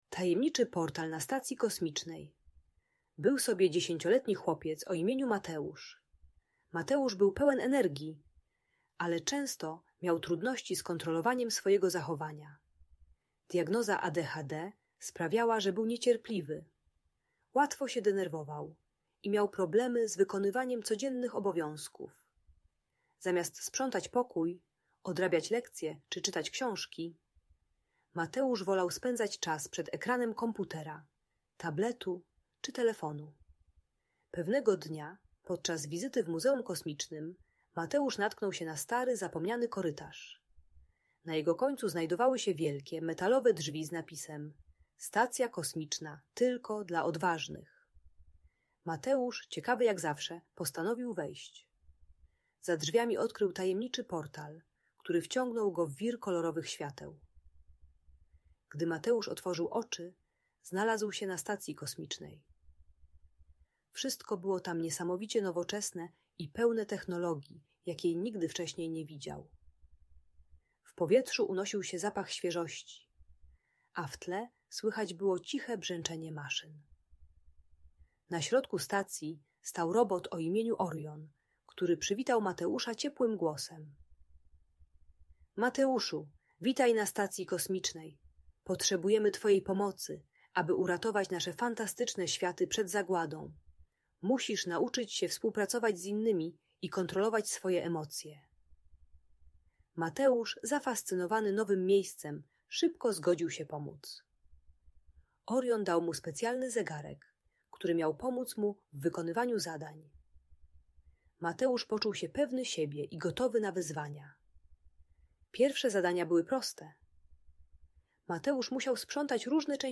Audiobajka o radzeniu sobie ze złością i impulsywnością.